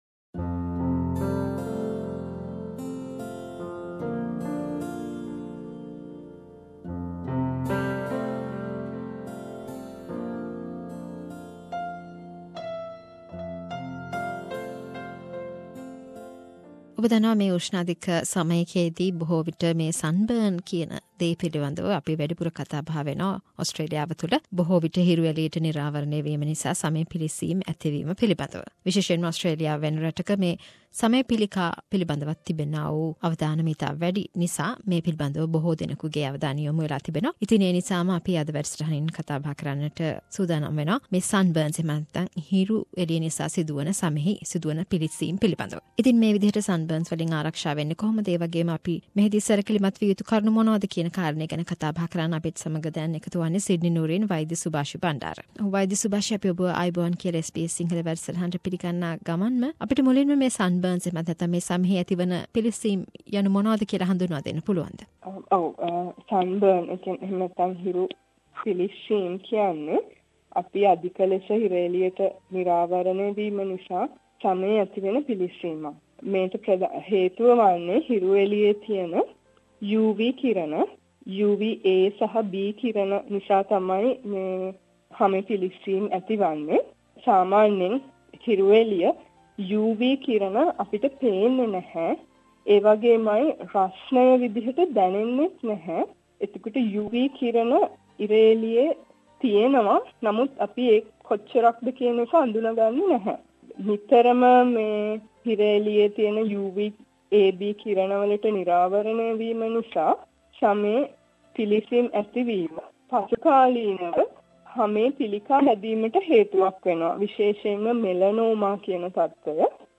A medical discussion about sunburns